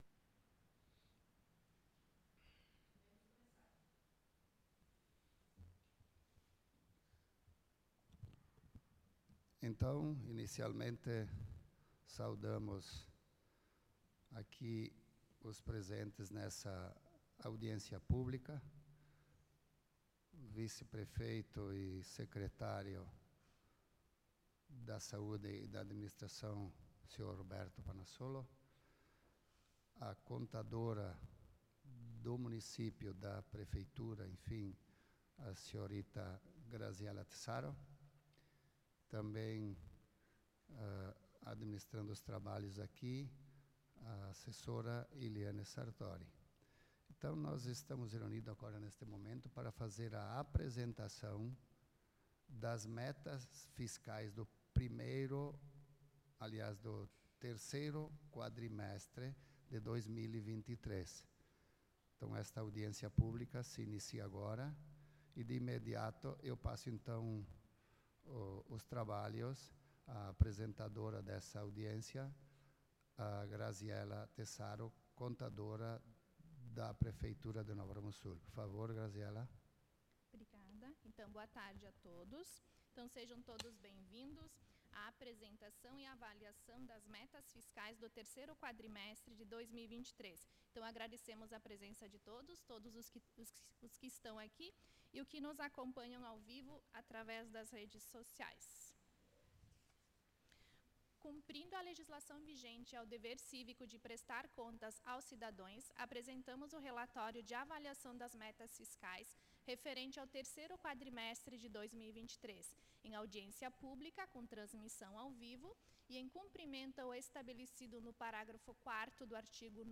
Audiência Pública
Câmara de Vereadores de Nova Roma do Sul